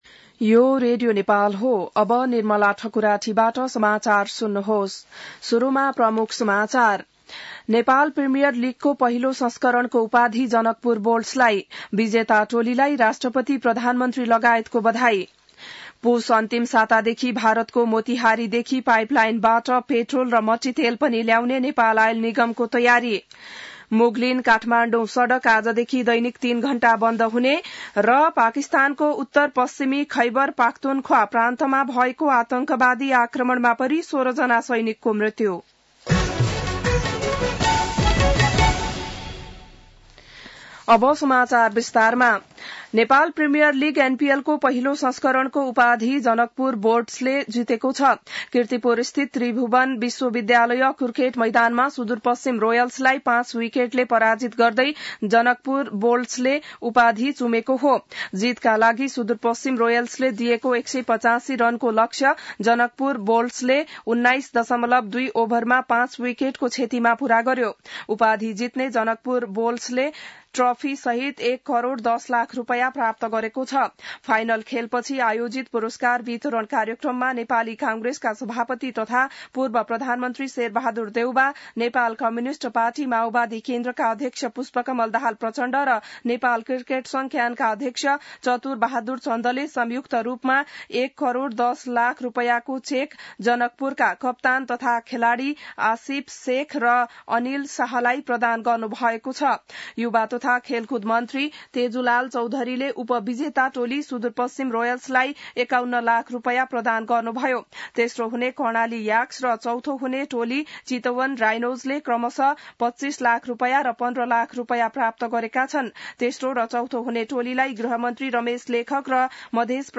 An online outlet of Nepal's national radio broadcaster
बिहान ९ बजेको नेपाली समाचार : ८ पुष , २०८१